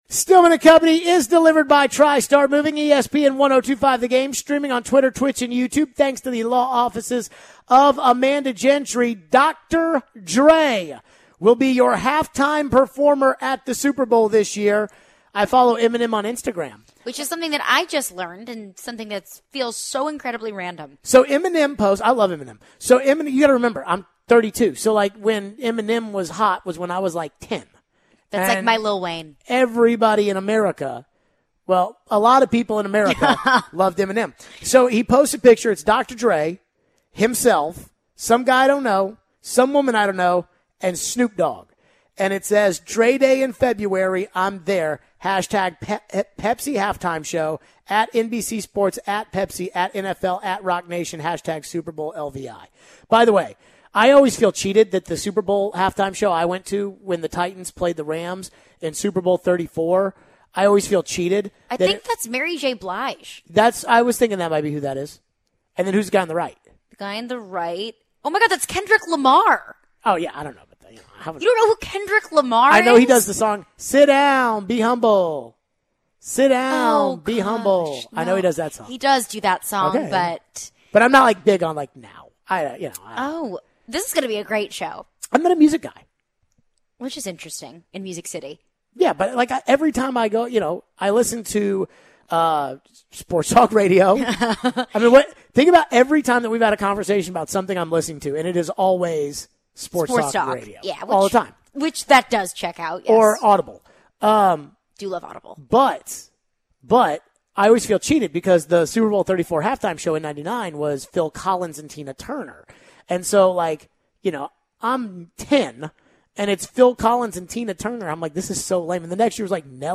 We take your calls and texts.